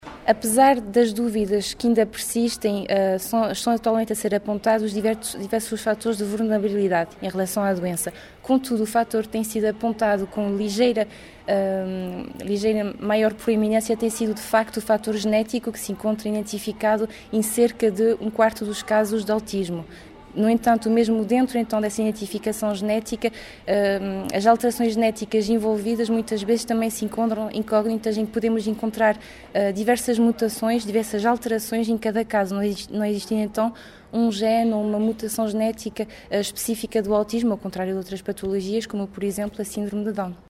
Declarações à margem da sessão “O Mundo do Autismo”, promovido pela Associação de Pais e Encarregados de Educação do Agrupamento de Escolas de Macedo de Cavaleiros, onde atualmente há uma criança que sofre de Perturbação do Espectro do Autismo (nova designação adotada).